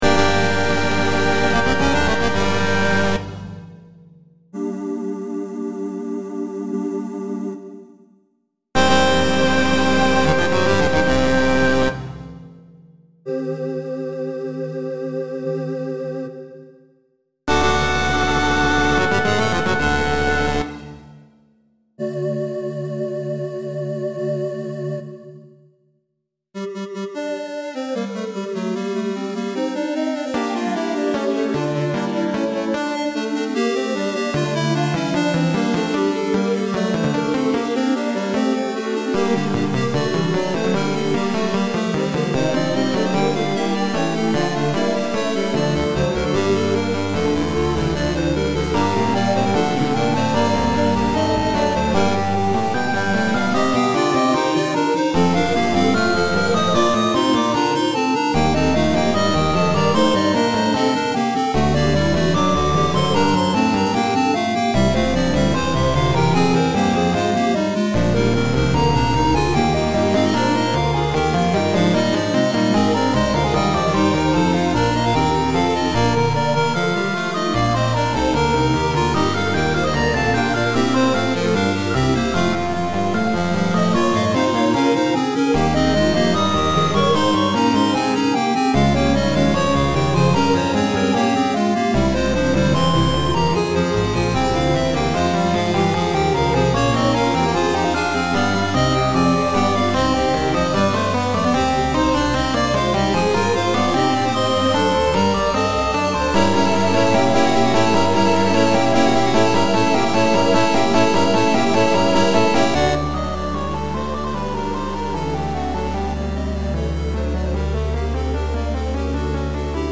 Stabat Mater Finale in chiptune style, time to attack and dethrone God